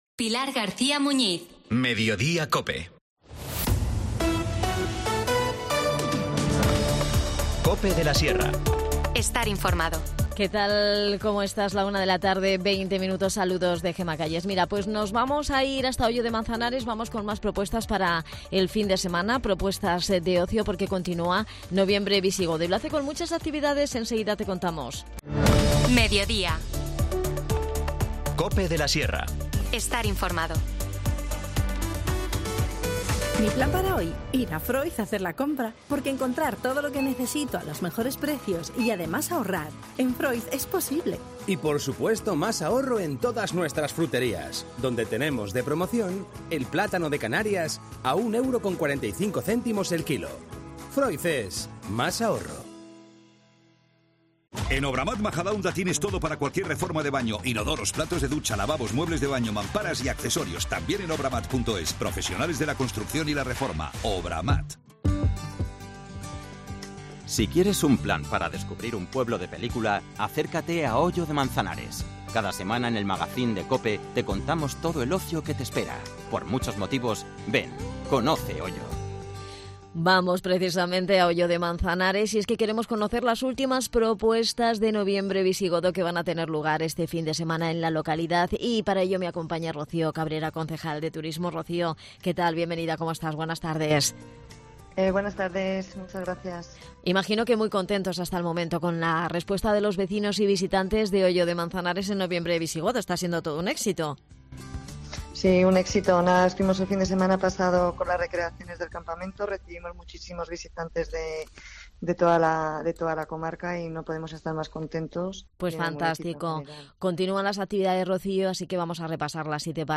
Nos detalla la programación Rocío Cabrera, concejal de Turismo.